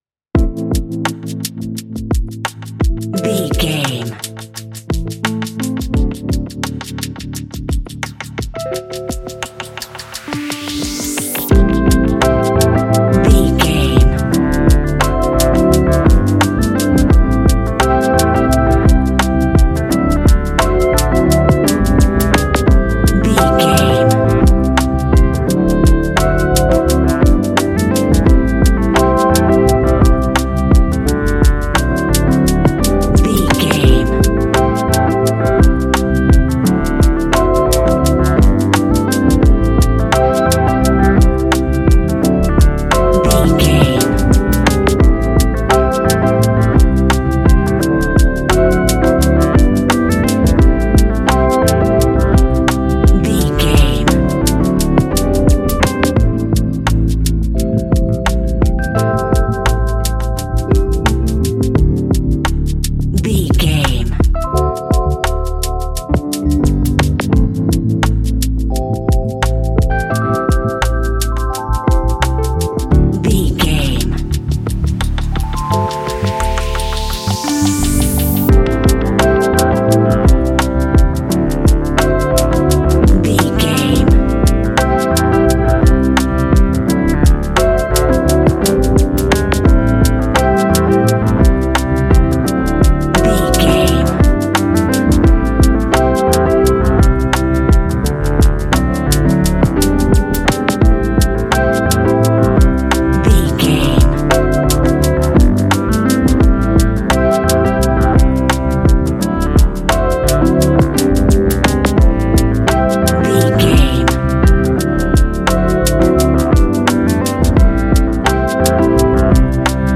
Ionian/Major
A♭
chilled
laid back
Lounge
sparse
new age
chilled electronica
ambient
atmospheric
morphing
instrumentals